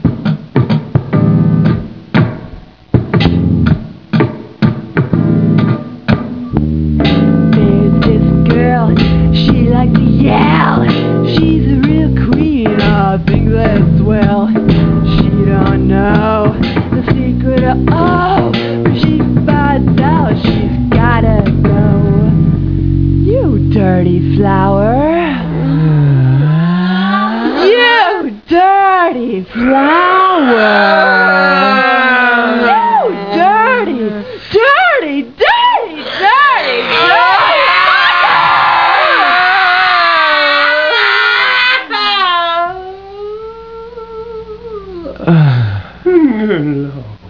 The unique punk nursery rhyme collective.
guitar and lead vocals
drums and vocals
bass and vocals